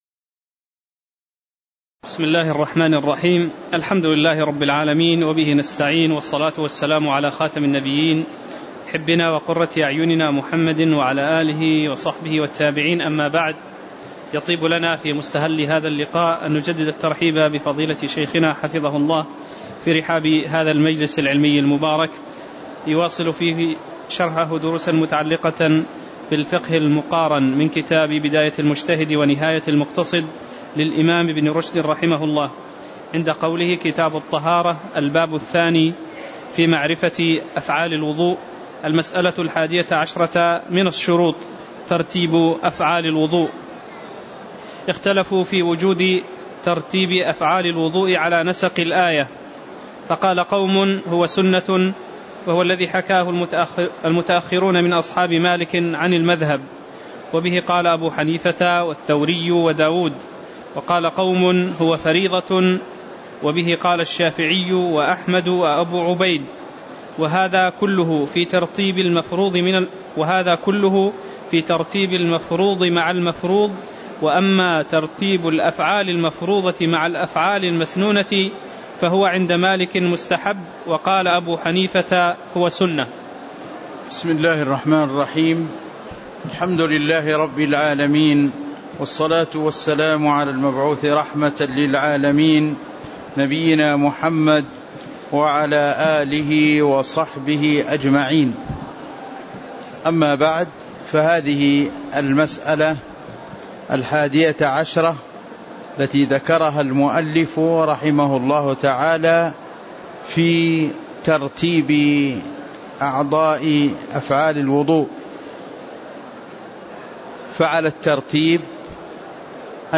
تاريخ النشر ١٥ رجب ١٤٣٩ هـ المكان: المسجد النبوي الشيخ